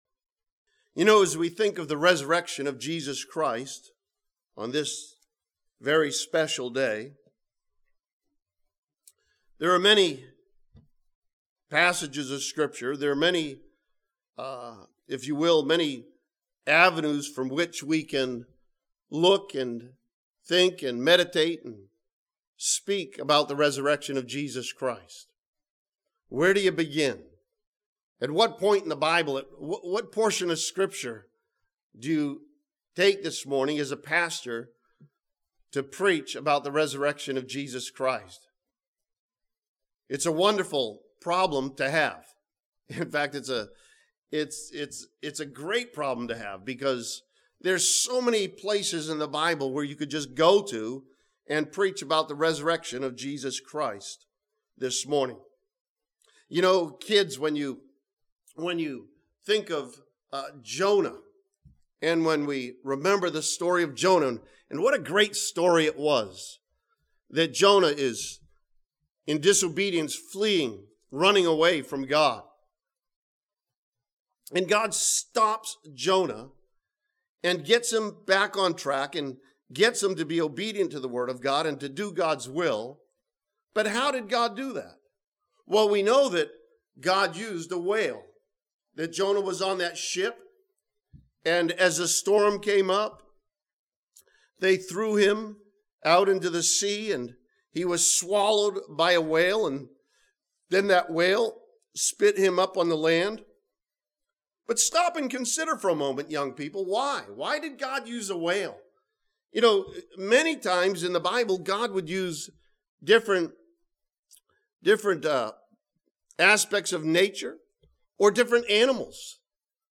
This sermon from Philippians chapter 3 challenges believers to understand, realize, and apply the victory that comes from knowing Him.